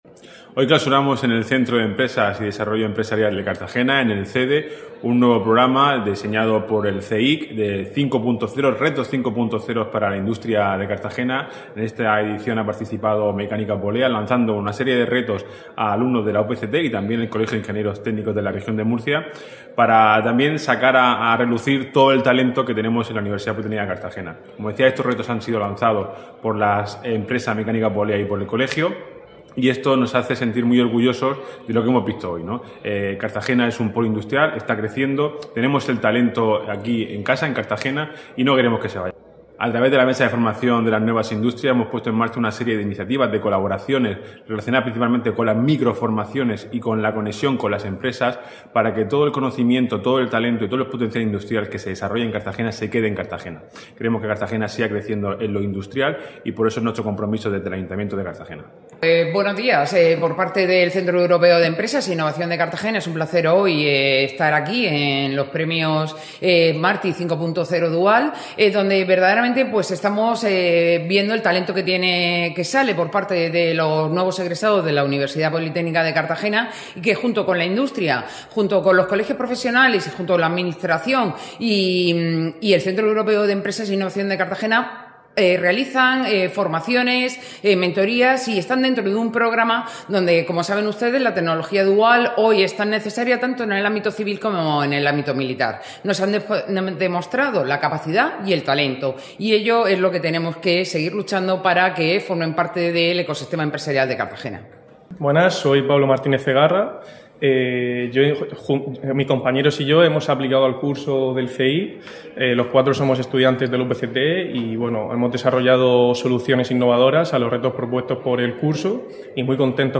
Enlace a Declaraciones del concejal de Empleo, Álvaro Valdés